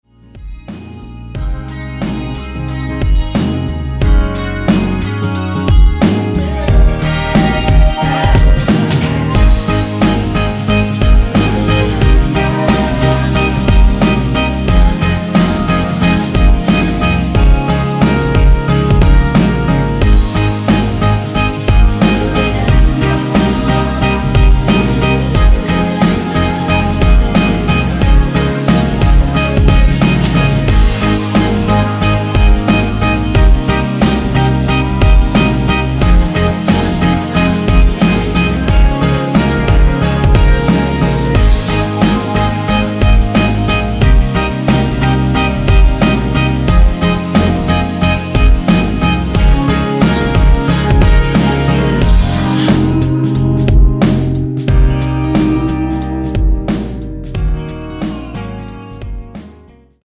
(-2)내린 코러스 포함된 MR 입니다.(미리듣기 참조)
Ab
앞부분30초, 뒷부분30초씩 편집해서 올려 드리고 있습니다.